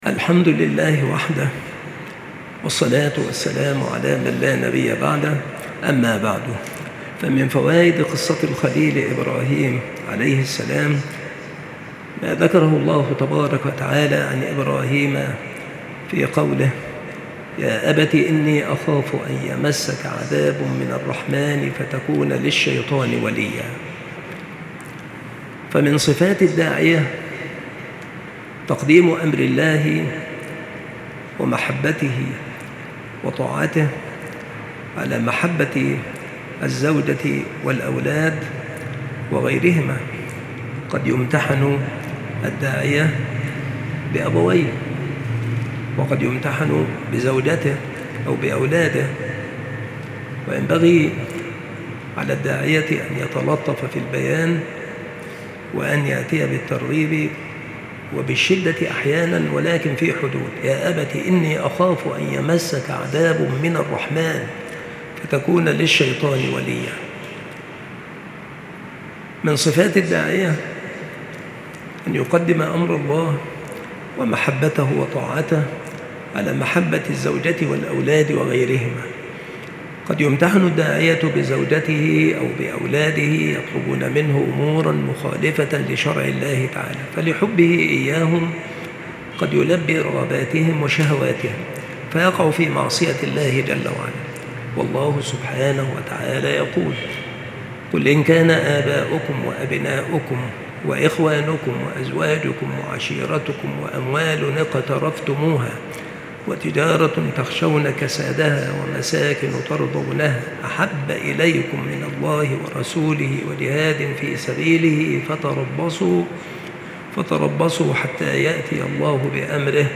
التصنيف قصص الأنبياء
بالمسجد الشرقي - سبك الأحد - أشمون - محافظة المنوفية - مصر